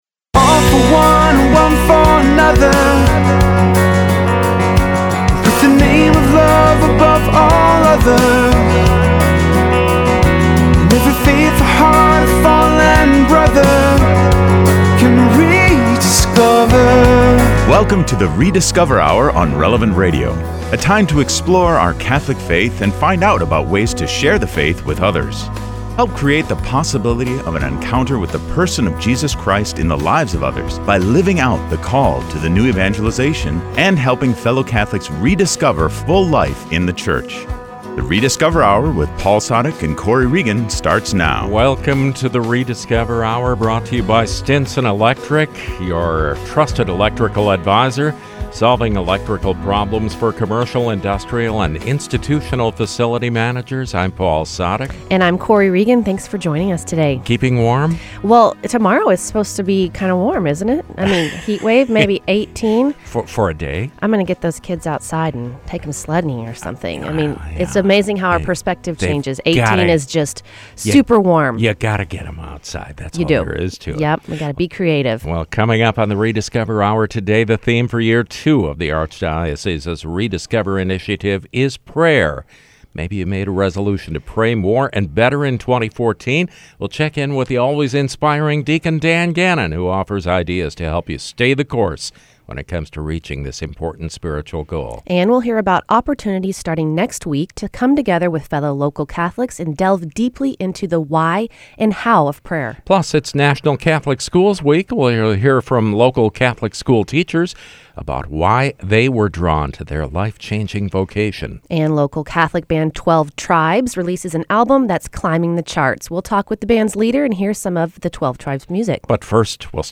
Hablamos con el líder de la banda y escuchamos algunas canciones rockeras llenas de fe.